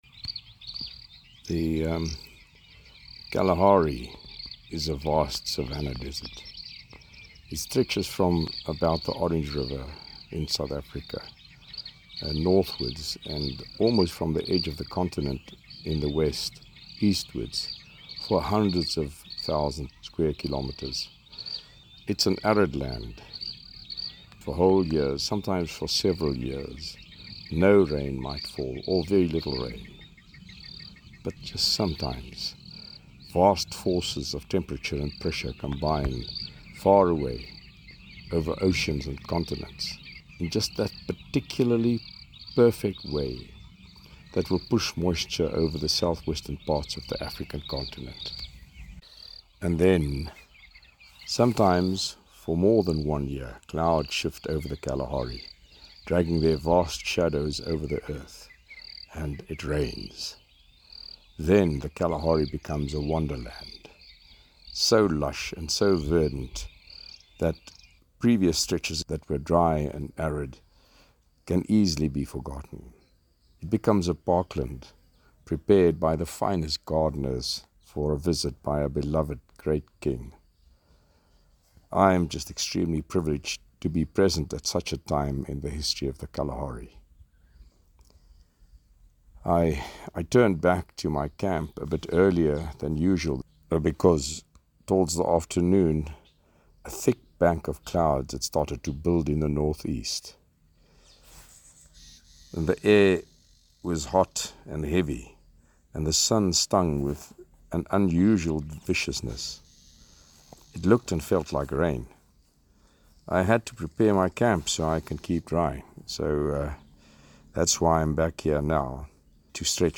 Here are my thoughts once I got back to my camp:
Storm-approaching-in-the-Kalahari-1.mp3